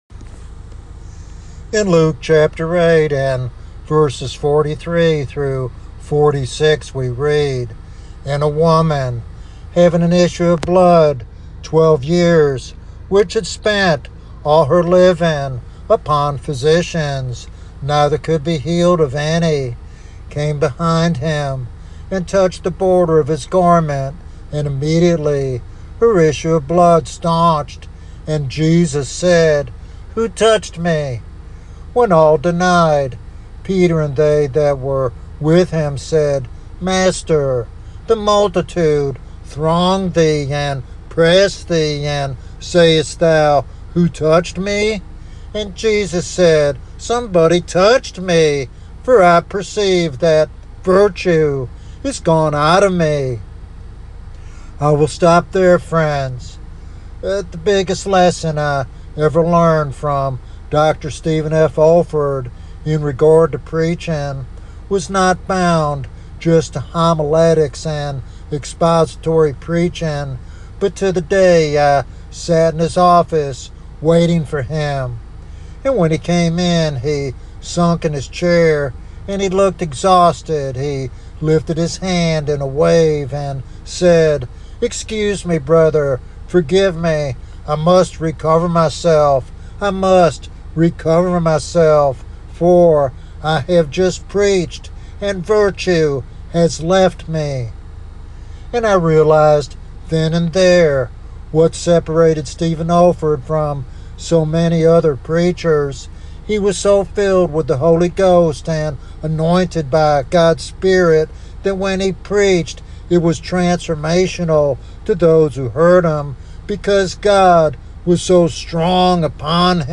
This sermon highlights the difference between mere words and Spirit-empowered ministry that transforms lives.